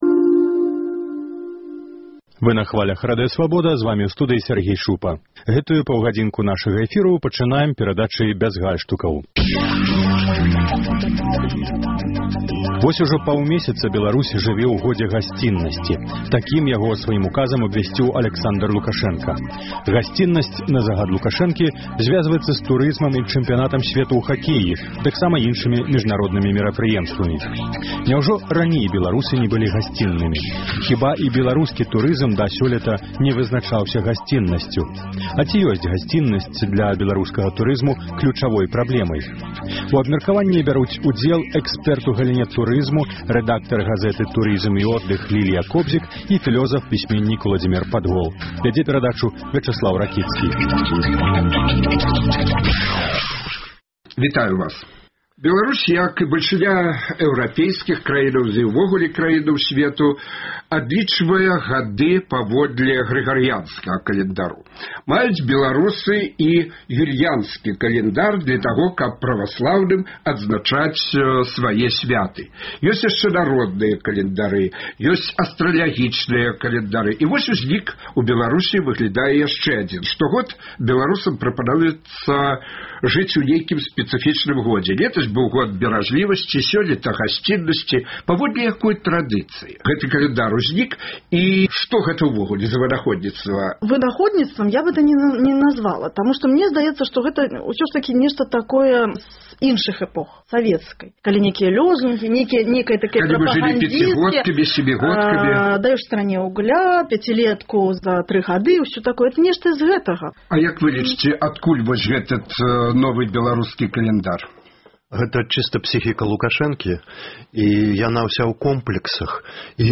У абмеркаваньні бяруць удзел экспэрт у галіне турызму